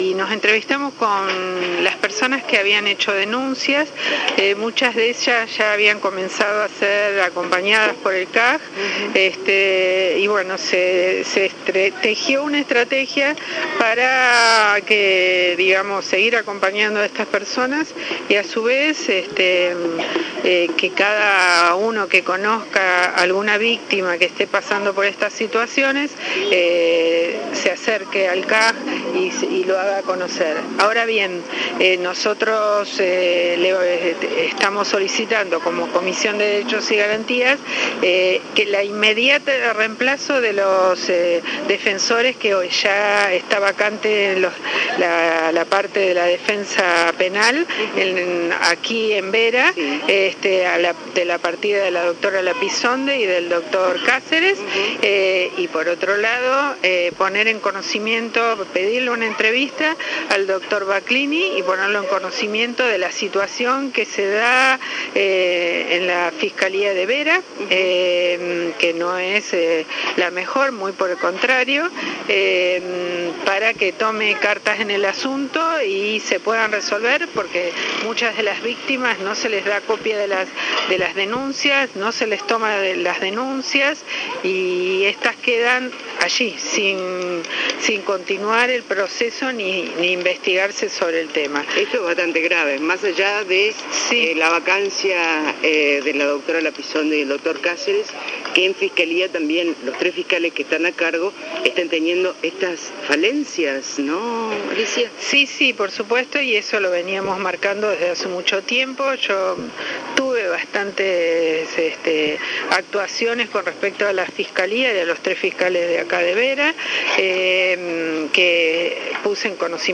En dialogo con FM ACTIVA, la diputada Gutiérrez habló sobre, lo que a su entender son “situaciones permanentes y reiteradas de torturas en la policía de Vera” y criticó el accionar de los fiscales de Vera.